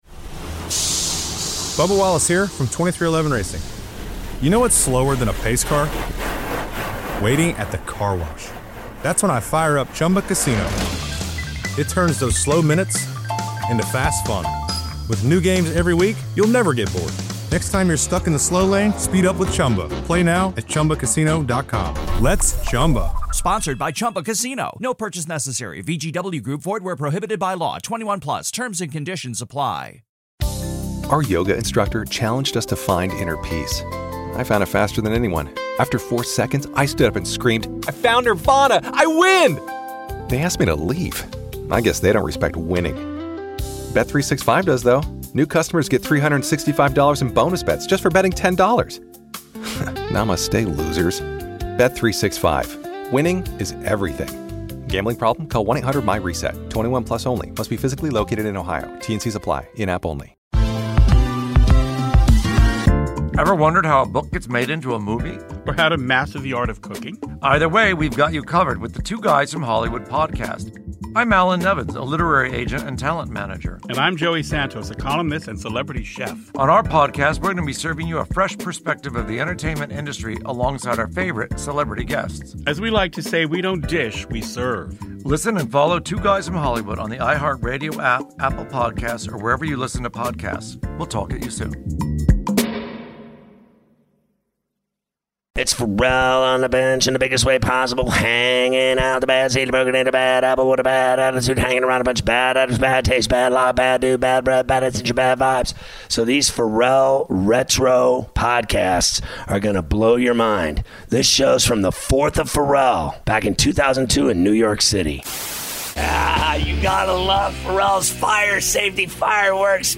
Listen back as Scott Ferrall does radio in New York on July 4, 2002